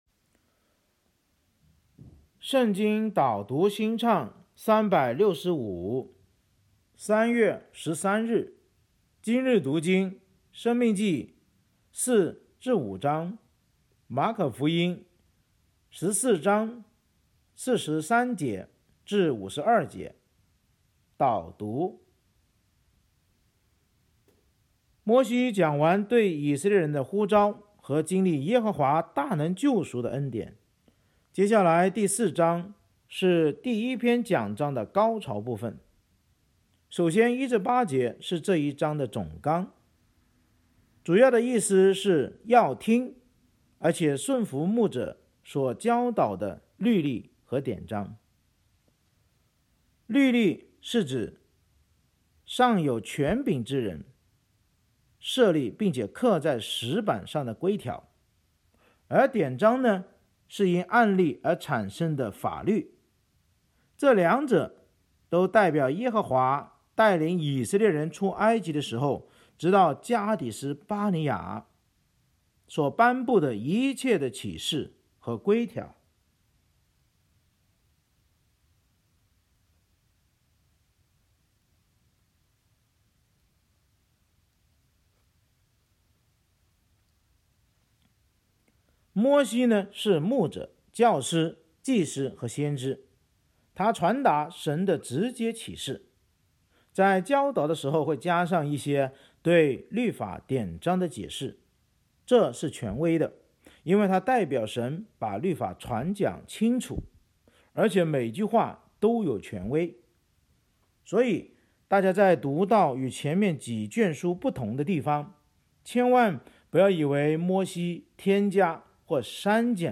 【经文朗读】（中文）